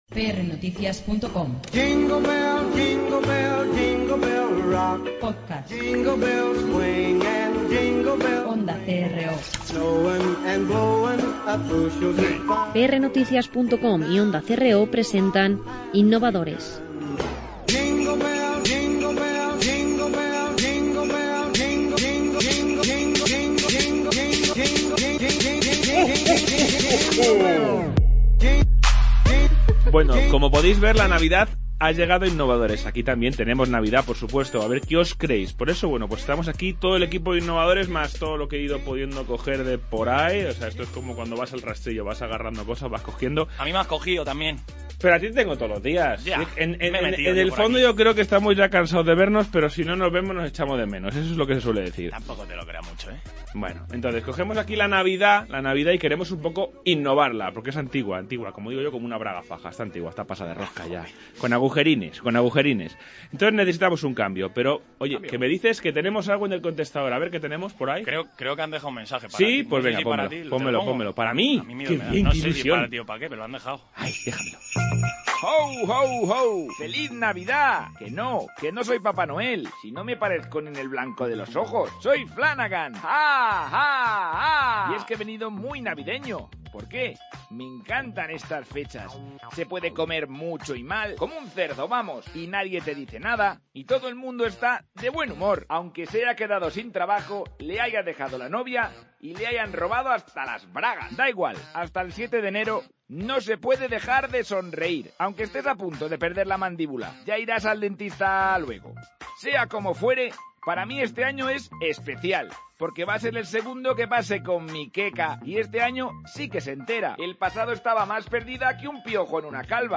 La Navidad ha hecho que nos reunamos todos, el equipo al completo de Innovadores, alrededor de una mesa.